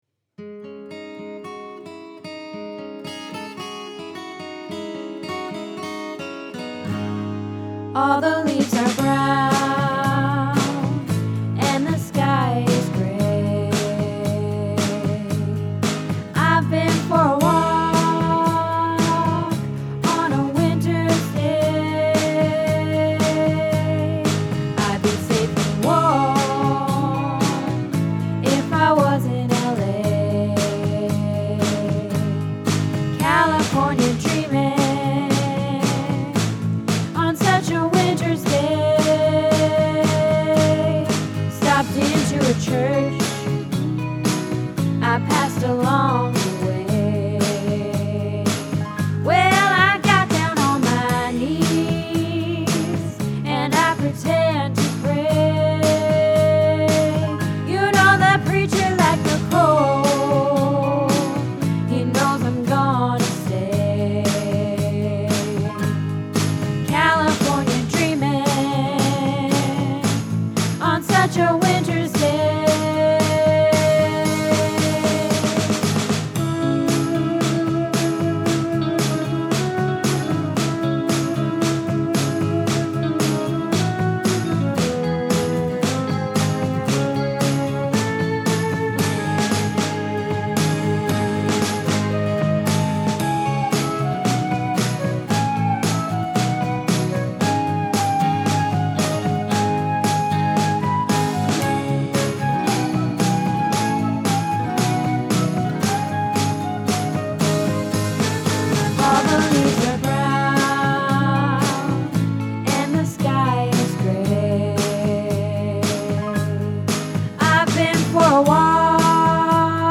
California Dreamin' - Alto